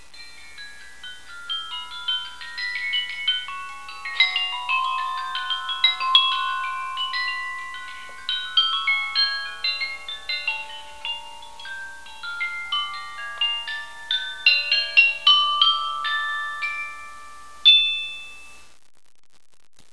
平成１０年４月１４日．１５日．１６日と本山興正寺に於いて例年の通りに春の法要が営まれました。
この法要を記念してサヌカイトと声明の合奏奉納がなされました。とても神秘的な音です。